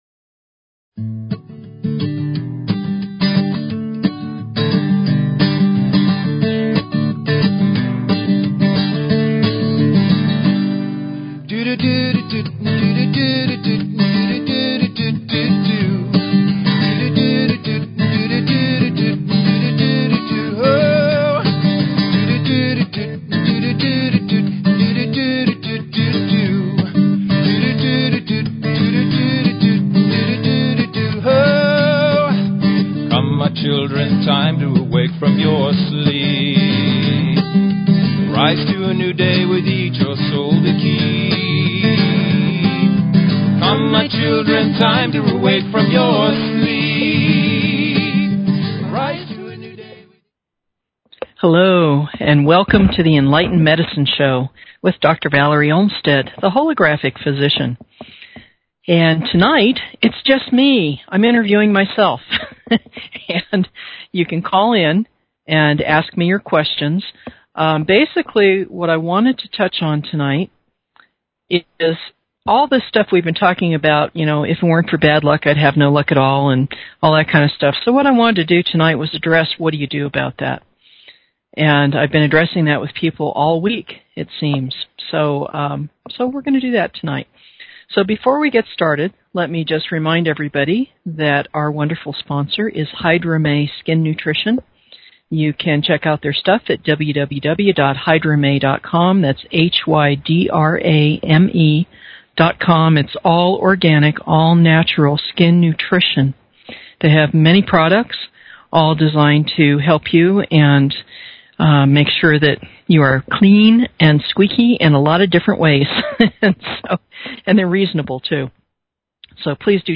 Talk Show Episode, Audio Podcast, Enlightened_Medicine and Courtesy of BBS Radio on , show guests , about , categorized as
Call-ins welcome--let's get deep into your reasons and opportunities, so you don't miss out!